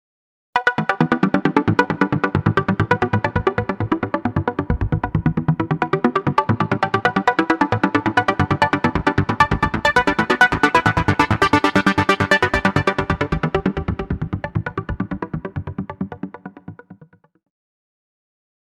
Ein Arpeggiator "bricht" einen gegriffenen Akkord, d.h. er zerlegt ihn in Einzeltöne, die mit einstellbarer Notenlänge hintereinander wiedergegeben werden.
Arpeggio Random
arp_rnd.mp3